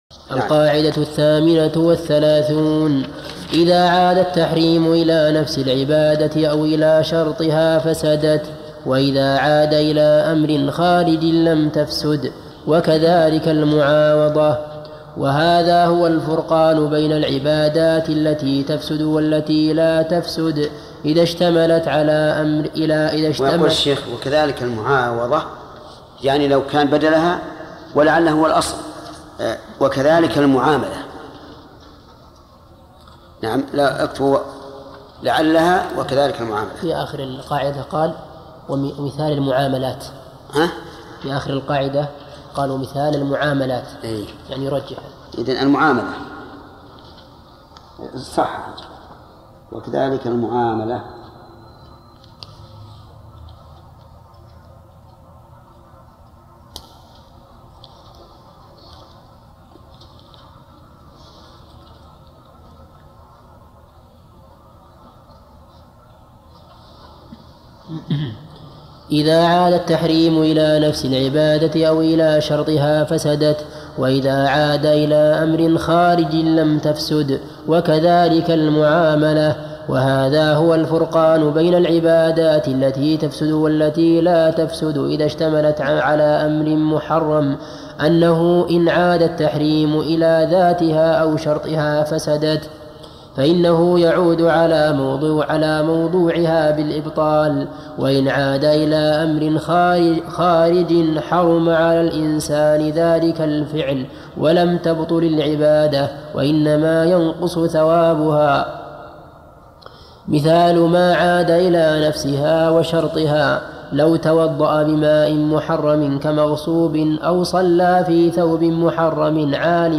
ارسل فتوى عبر "الواتساب" ينبوع الصوتيات الشيخ محمد بن صالح العثيمين فوائد من التعليق على القواعد والأصول الجامعة - شرح الشيخ محمد بن صالح العثيمين المادة 60 - 148 القاعدة 38 إذا عاد التحريم إلى نفس العبادة أو...